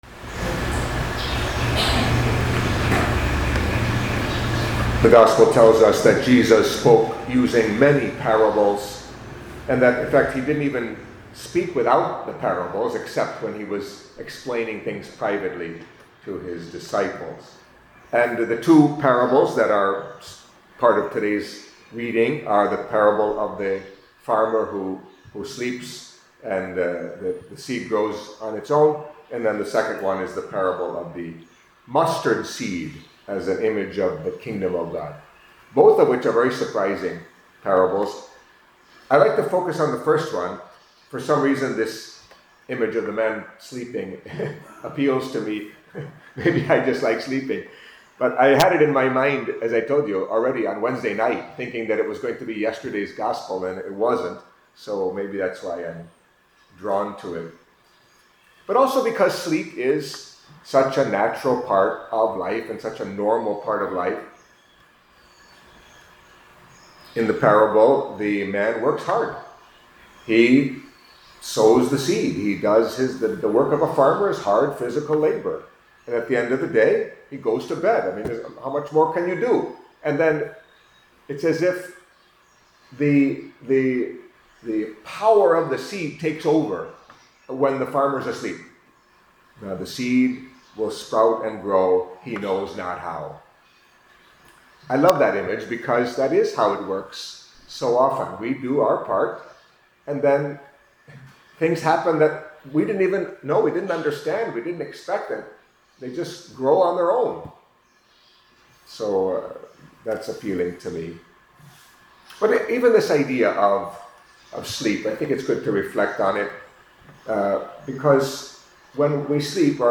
Catholic Mass homily for Friday of the Third Week in Ordinary Time